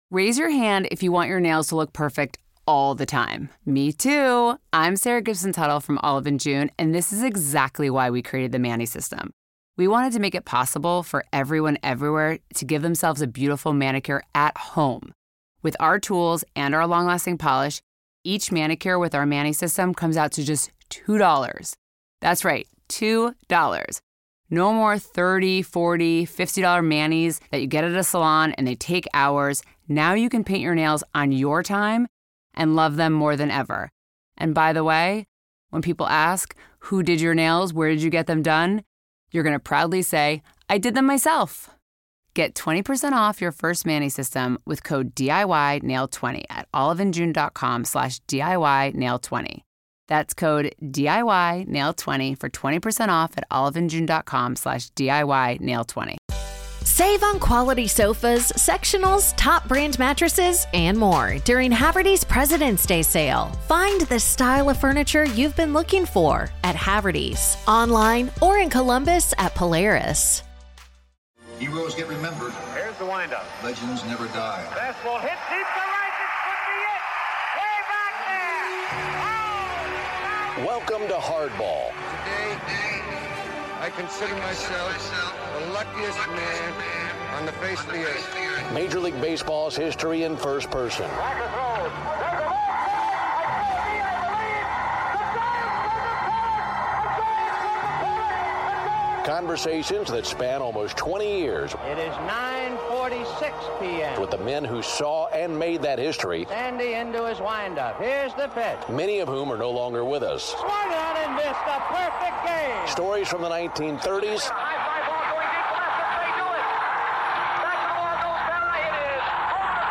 Joe Morgan was one of - if not the greatest - 2B off All-Time. You'll hear his story of how he overcame the "too small" tag to become a 2X MVP and leader. I spoke with Joe later in his life..and his want to reflect on his life and career was genuinely moving.